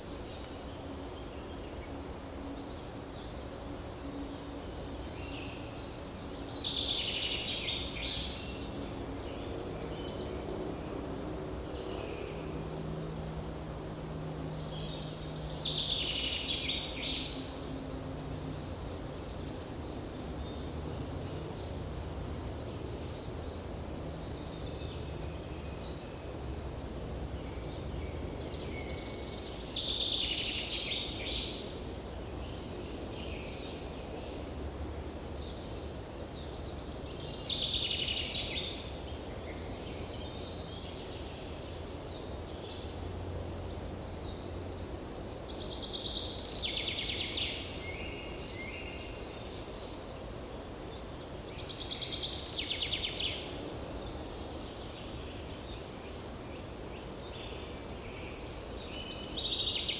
Monitor PAM
Columba oenas
Sitta europaea
Fringilla coelebs
Regulus ignicapilla
Certhia familiaris
Certhia brachydactyla
Cyanistes caeruleus
Lophophanes cristatus